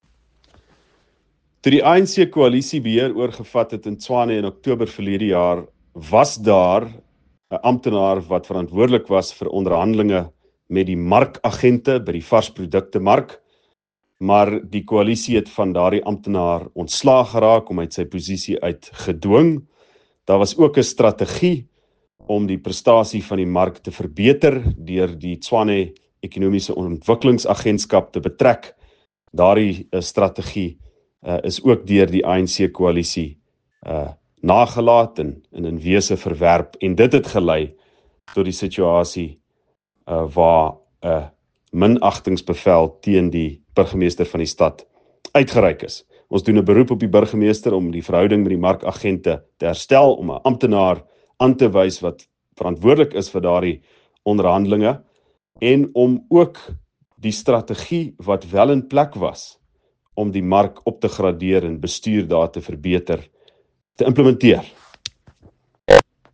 Note to Editors: Please find an English and Afrikaans soundbite by Ald Cilliers Brink